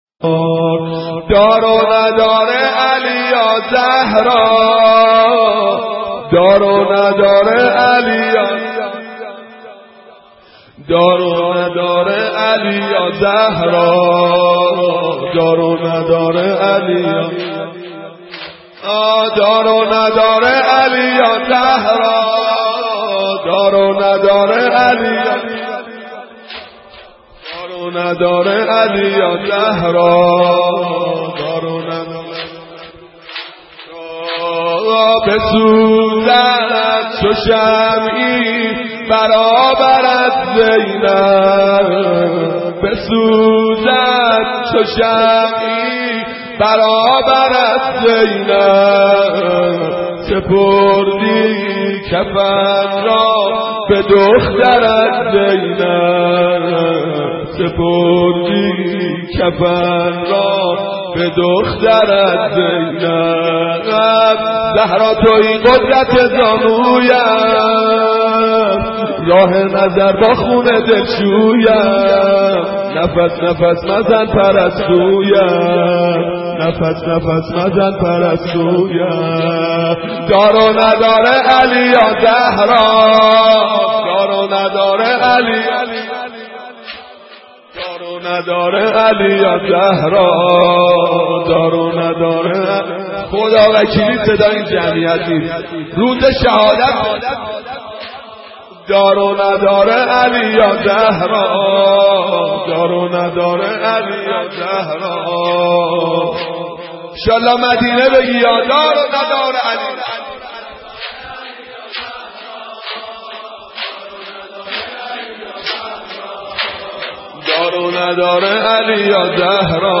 دانلود مداحی دار و ندار علی - دانلود ریمیکس و آهنگ جدید
مرثیه خوانی در سوگ شهادت حضرت زهرا (س)